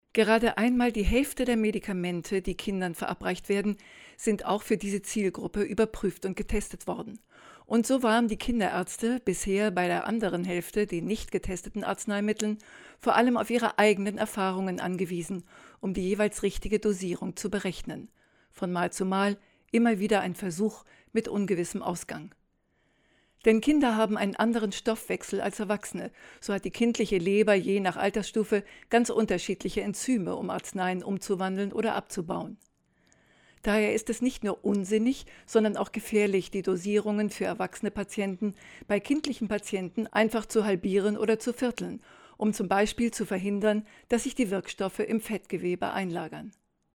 professionelle deutsche Sprecherin.
Sprechprobe: Werbung (Muttersprache):
german female voice over artist